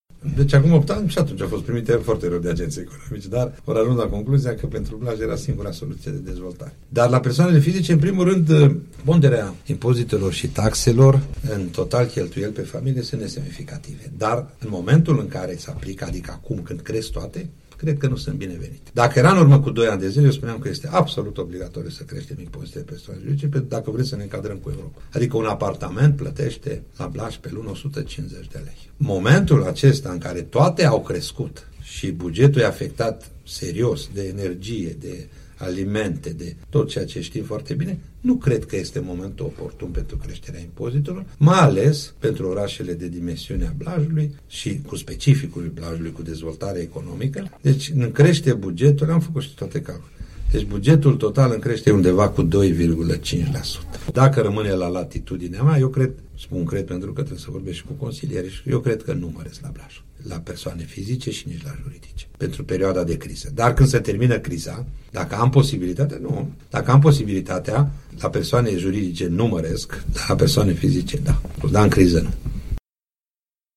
Este declarația făcută la Unirea FM de edilul blăjean, Gheorghe Valentin Rotar.